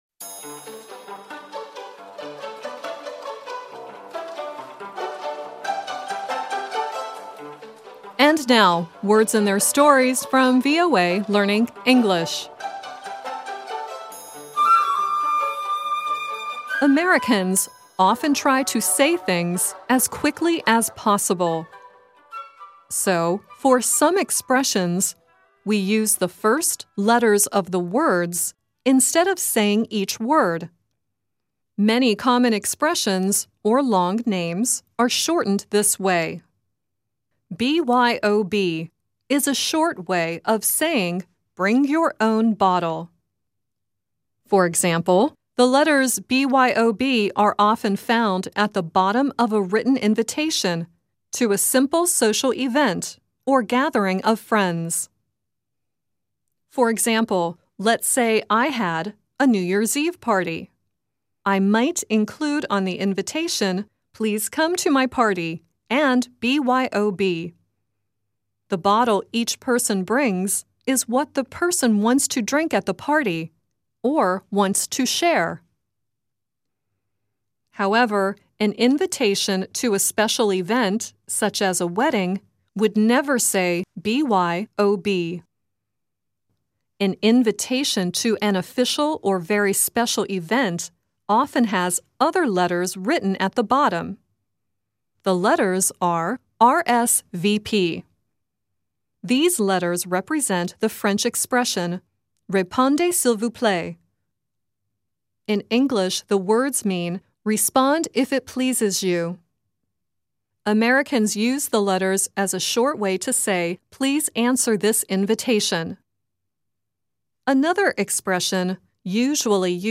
Kalau bisa diusahakan bacanya mirip dengan native speaker dibawah ini, oke..